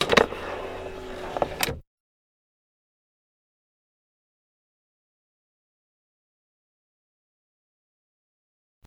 Car Power Seatbelts Sound
transport